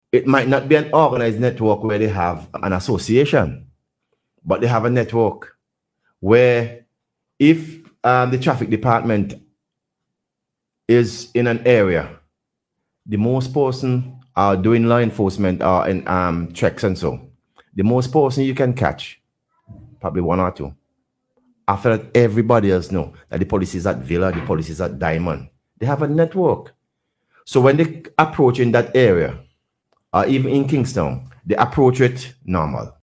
Speaking on radio recently, Browne said as a result of this, it is difficult to ticket public transport operators for vehicular offences.